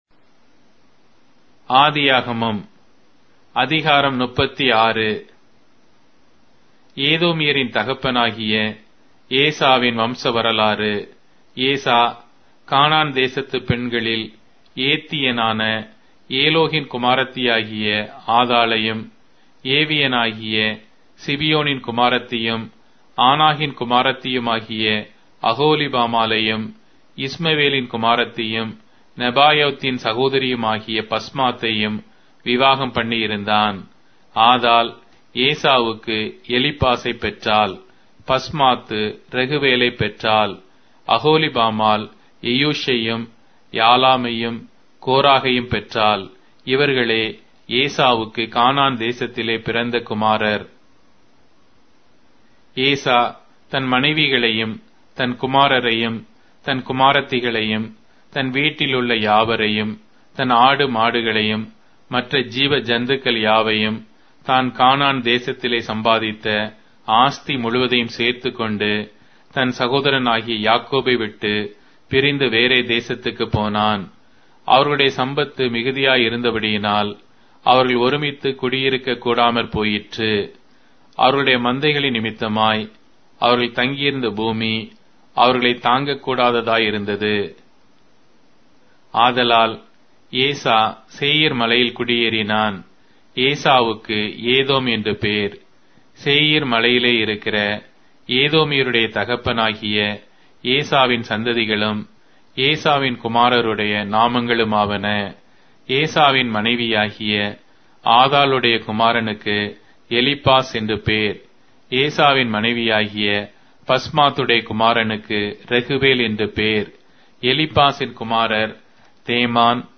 Tamil Audio Bible - Genesis 43 in Gntbrp bible version